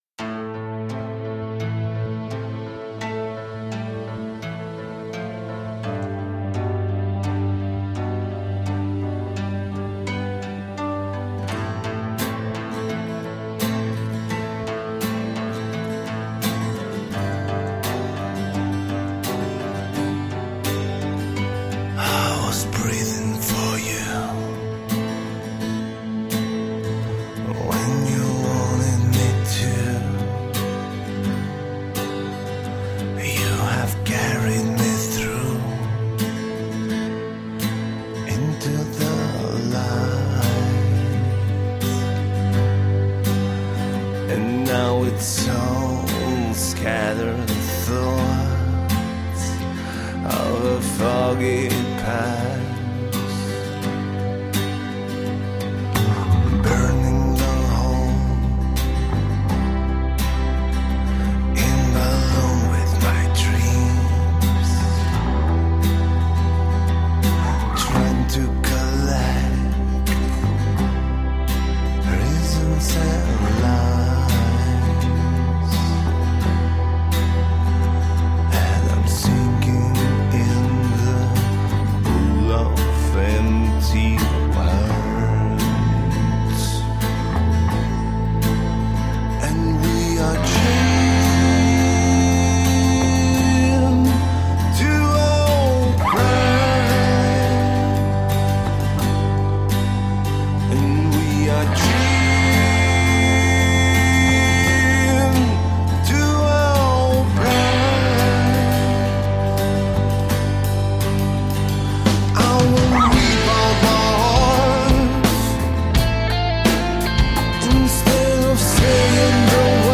Rock, alternative rock, indie rock, psych rock.
Tagged as: Alt Rock, Rock, Hard Rock, Prog Rock